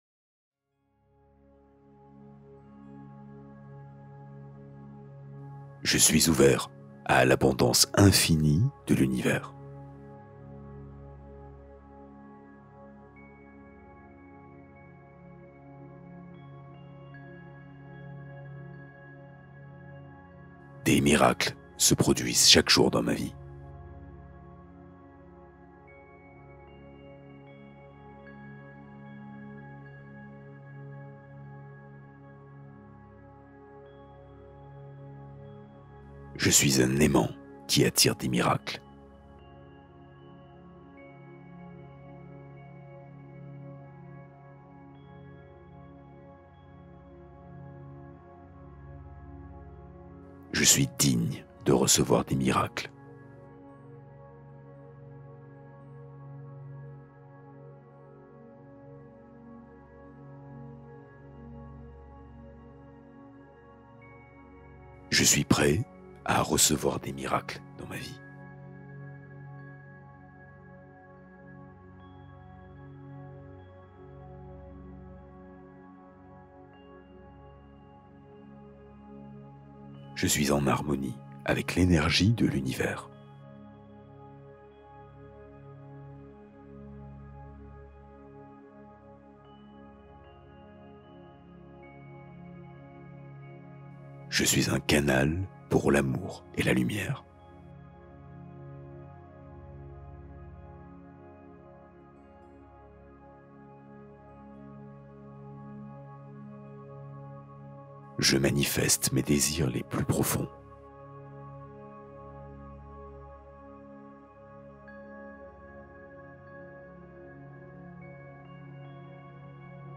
Fréquence 999 Hz : soutien vibratoire pour phases de transition